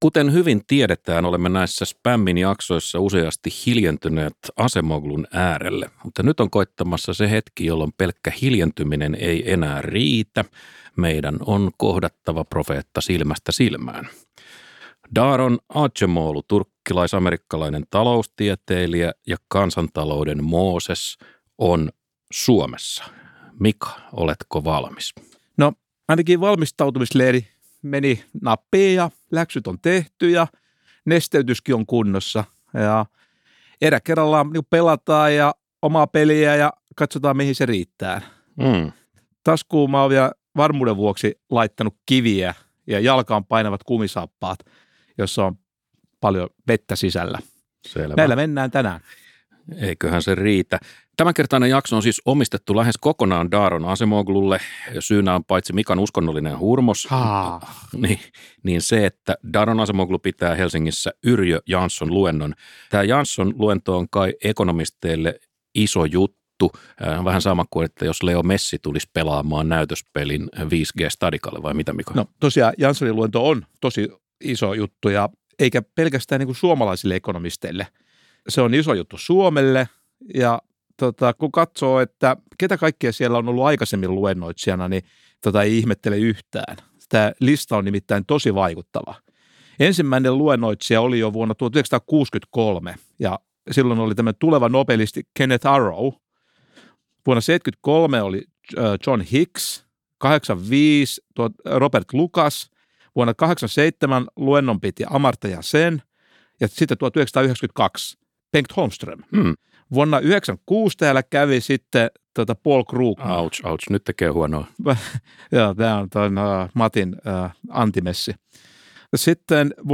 kun taloustieteilijä Daron Acemogu saapuu studioon kesken Daron Acemoglu -teemaisen lähetyksen.